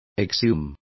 Complete with pronunciation of the translation of exhume.